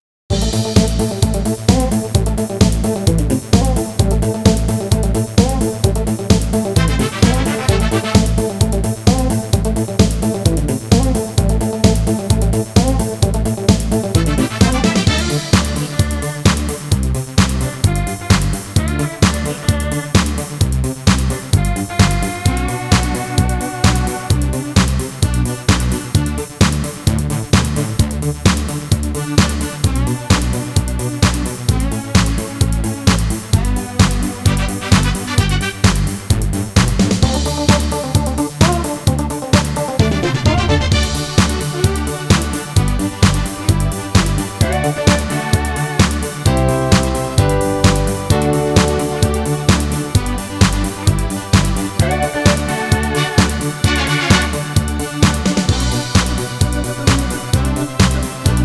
固件firm230328中，增加了新的“舞曲”功能，固件中给内置了30+首劲爆舞曲。
舞曲片段3